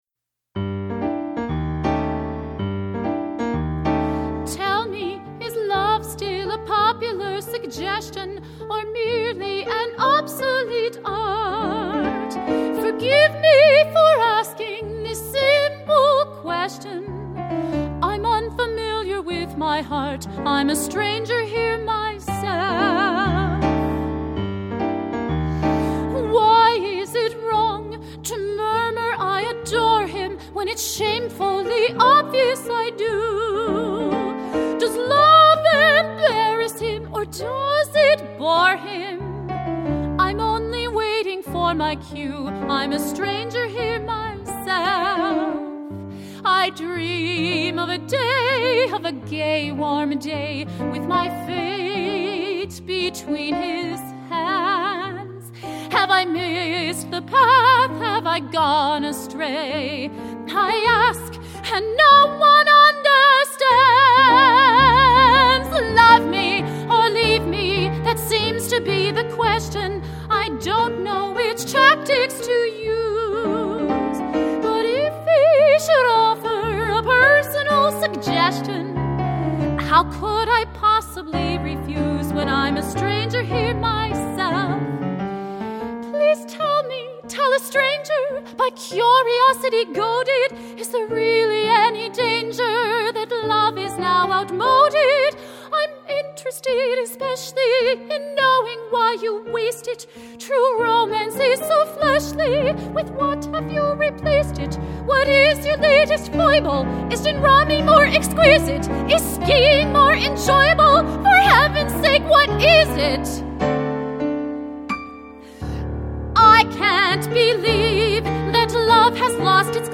Performance Samples
piano
West Hartford, CT